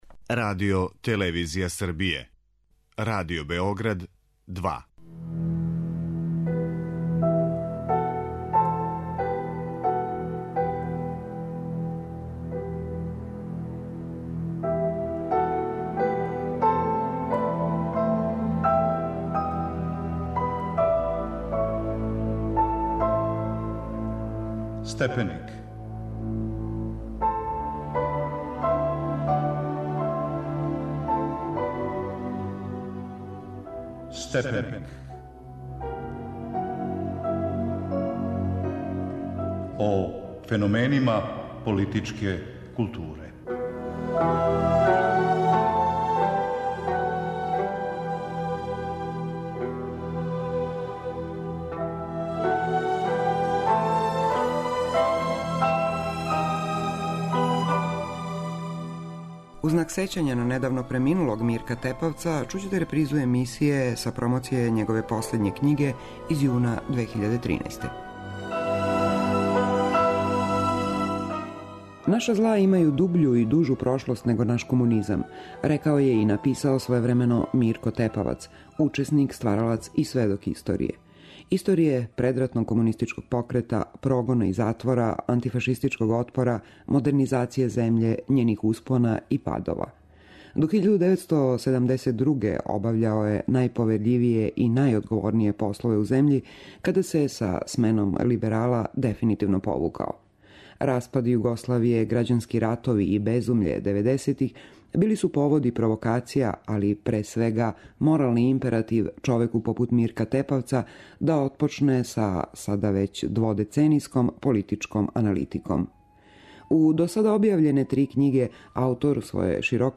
У знак сећања на Мирка Тепавца слушаћете репризу емисије снимљене пре годину дана на промоцији његове последње књиге.